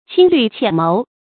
輕慮淺謀 注音： ㄑㄧㄥ ㄌㄩˋ ㄑㄧㄢˇ ㄇㄡˊ 讀音讀法： 意思解釋： 考慮不全面，計劃不周密。